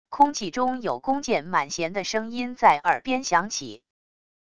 空气中有弓箭满弦的声音在耳边响起wav音频